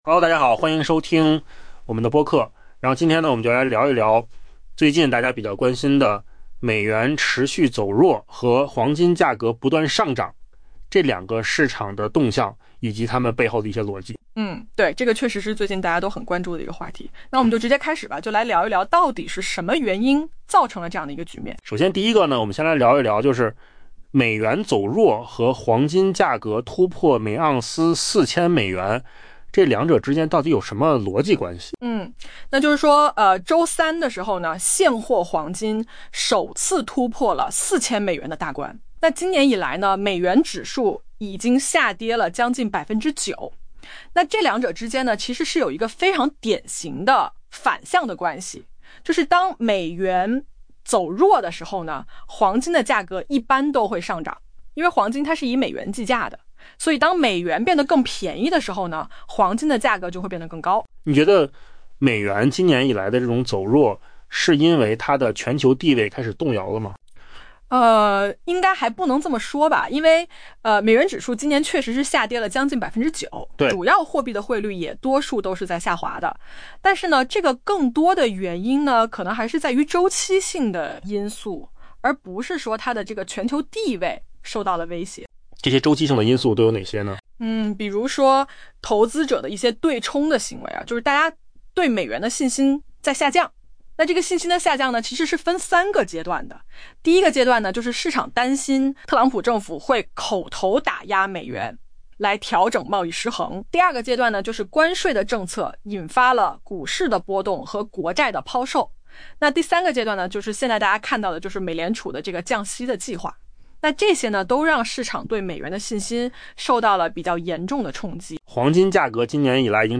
AI播客：换个方式听新闻 下载mp3
音频有扣子空间生成